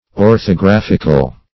orthographical - definition of orthographical - synonyms, pronunciation, spelling from Free Dictionary
\Or`tho*graph"ic*al\, a. [Cf. F. orthographique, L.